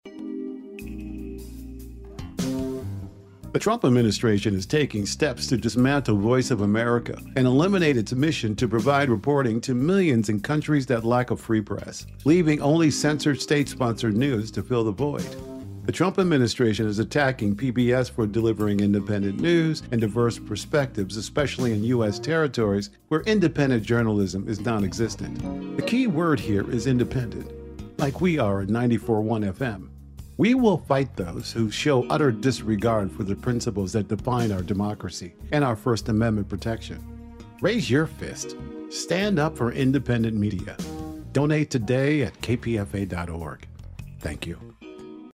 But numerous listeners who may disagree on other issues have all expressed strong disapproval of a promotional pitch recording currently airing on KPFA, that defends and praises the so-called “Voice of Americaˮ (VOA) and compares it to KPFA as an “independent” media outlet under attack by Trump.